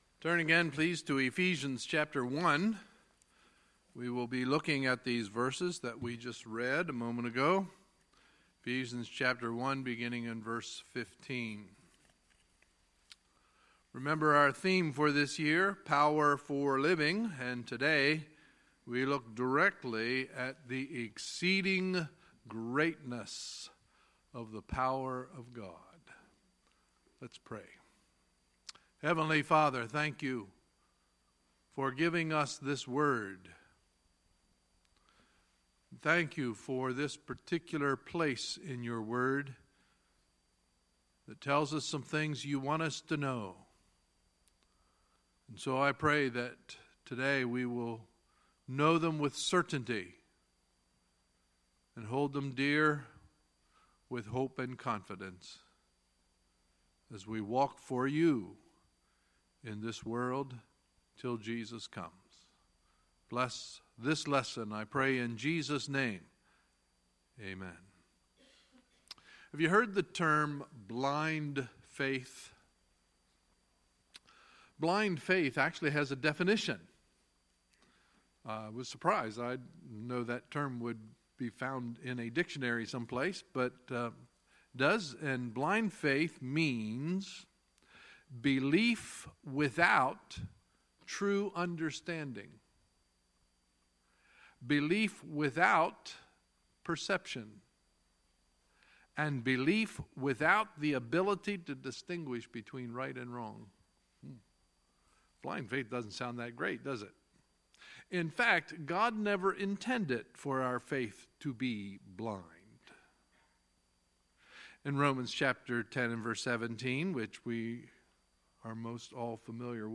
Sunday, August 13, 2017 – Sunday Morning Service
Sermons